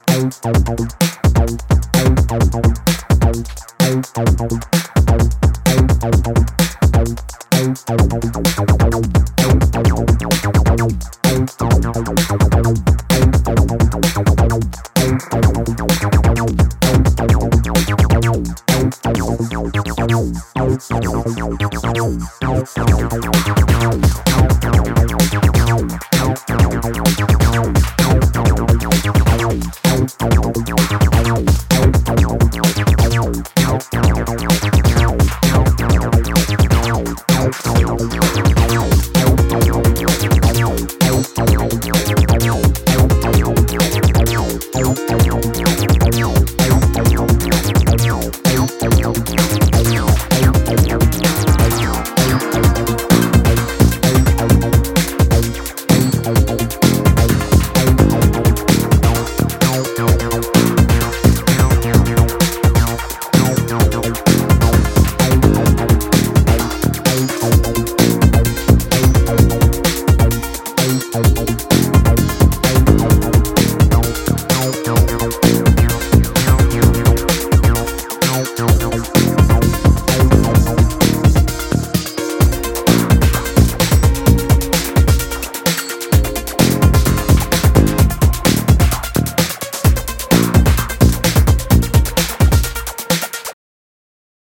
可憐なメロディに癒されるメロウ・アシッド